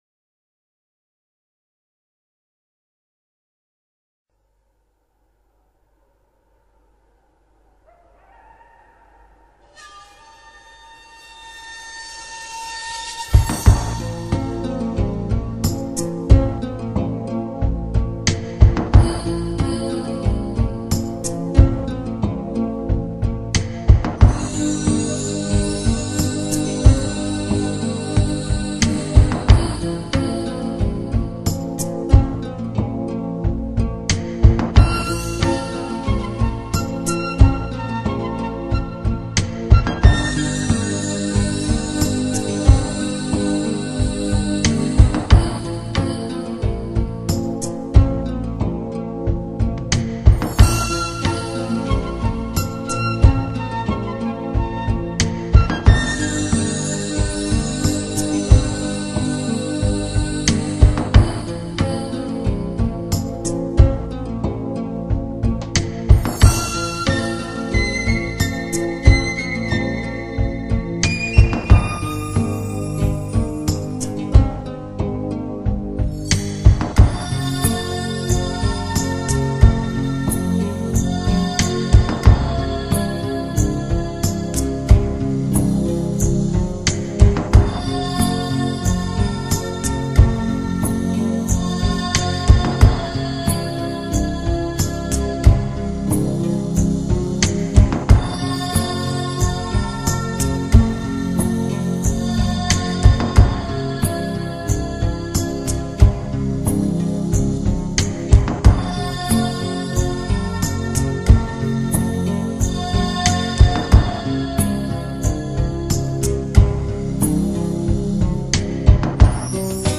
专辑介绍: 从EQ到AQ的新世纪音乐，旋律优美 舒缓神经 控制情绪突破 逆境!
本专缉收录的多首新世纪音乐，不单是一些旋律悦耳优美， 使人心境开朗的，更是能平衡思绪，使人回复状态的灵性音乐。
本专缉音质品相160K，声响绝对一流，精品奉献。